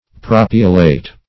Propiolate \Pro"pi*o*late\, n. A salt of propiolic acid.